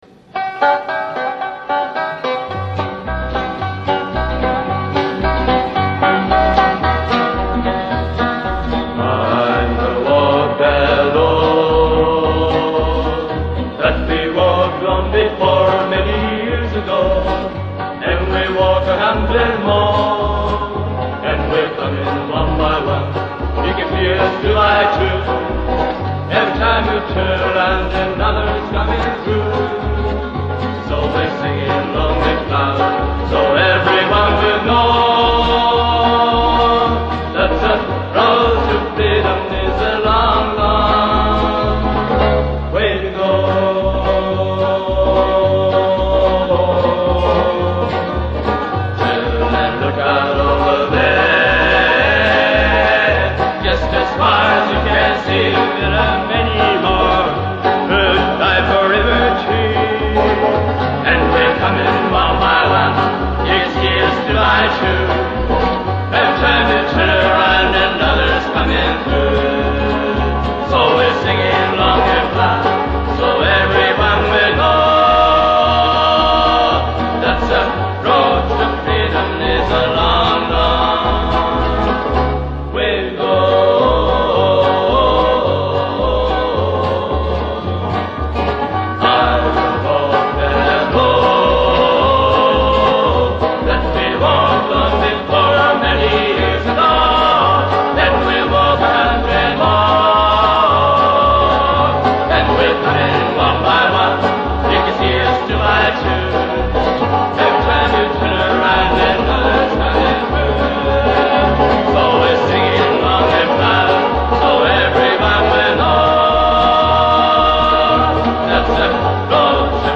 Japanese folk groups